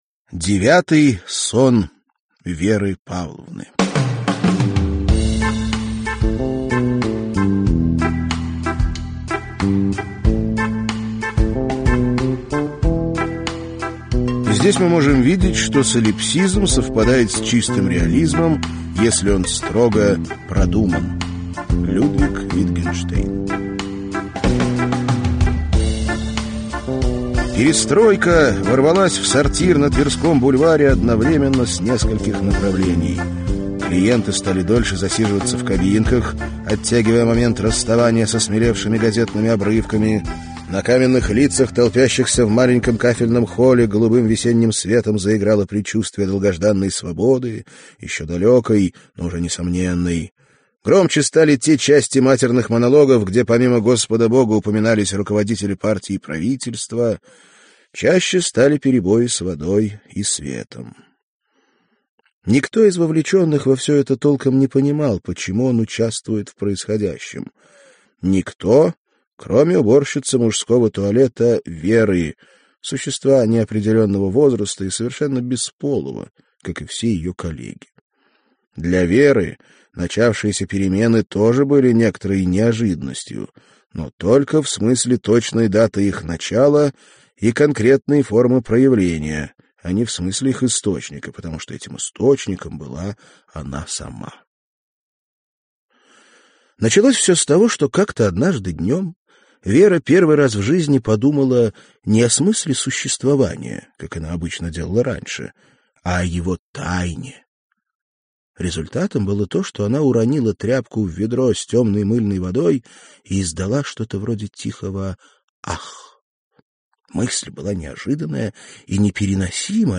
Аудиокнига Девятый сон Веры Павловны | Библиотека аудиокниг
Прослушать и бесплатно скачать фрагмент аудиокниги